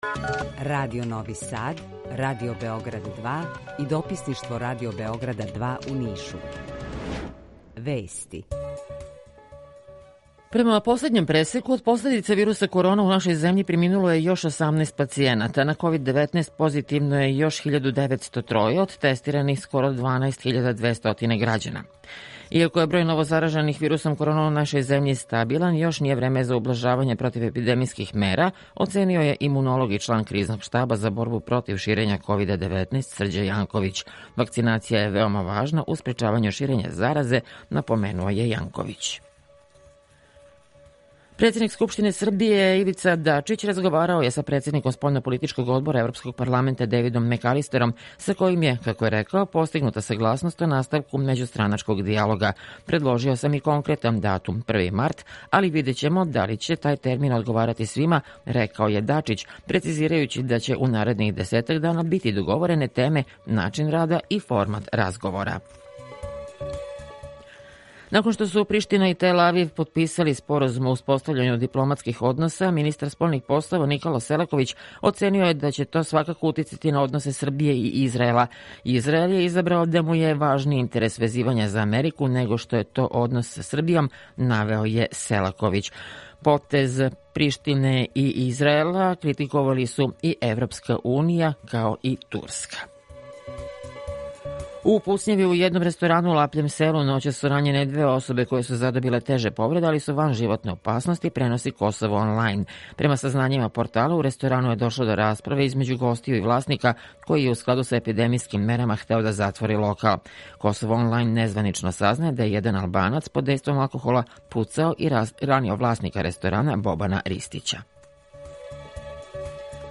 Укључење Радија Косовска Митровица
Јутарњи програм из три студија
У два сата, ту је и добра музика, другачија у односу на остале радио-станице.